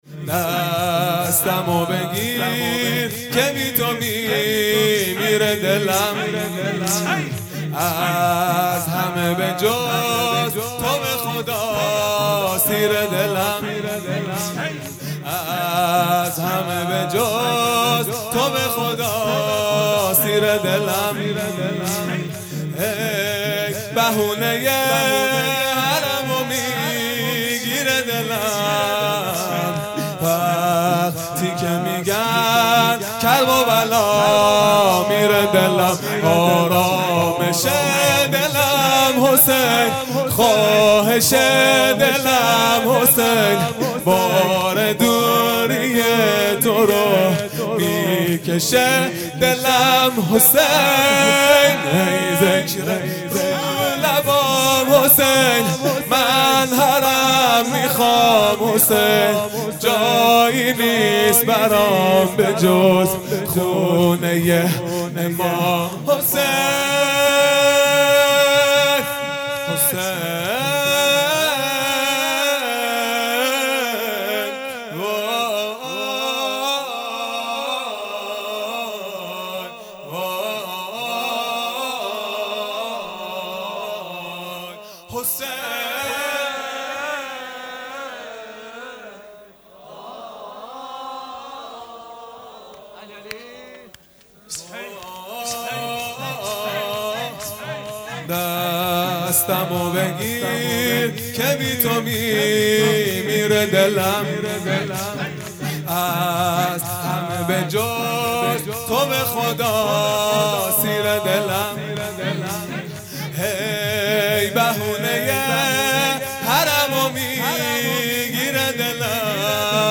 شور | دستمو بگیر که بی تو میمیره دلم | پنجشنبه ۲۱ مرداد ۱۴۰۰
دهه اول محرم الحرام ۱۴۴۳ | شب چهارم | پنجشنبه ۲1 مرداد ۱۴۰۰